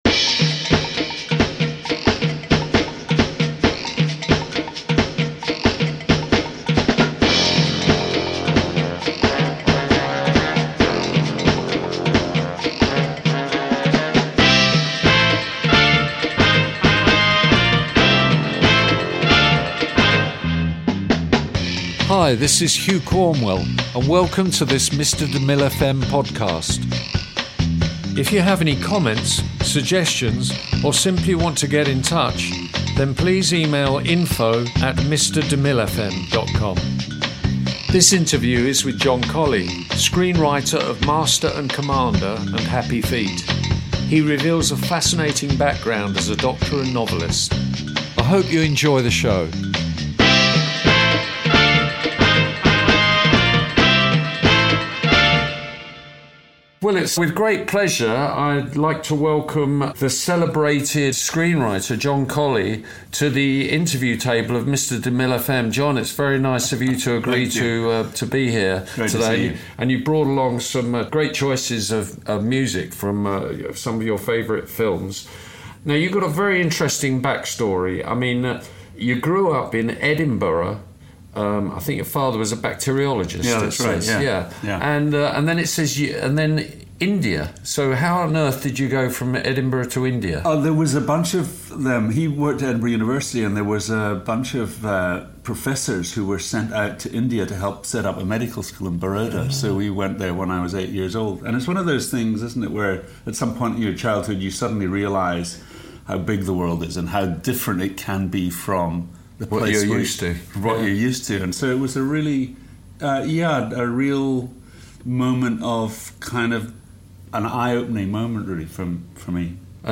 INTERVIEW JOHN COLLEE
This interview is with John Collee, screenwriter of 'Master And Commander' and 'Happy Feet'. He reveals a fascinating background as a doctor and novelist.